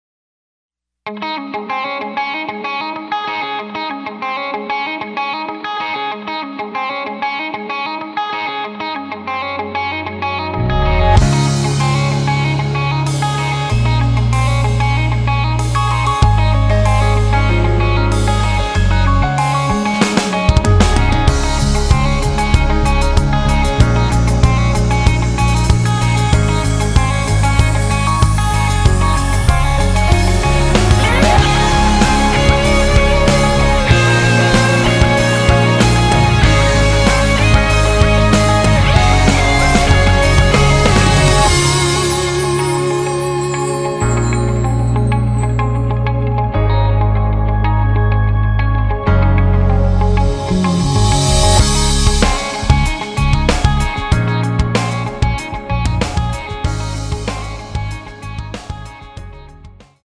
[공식 음원 MR]
키 Db 가수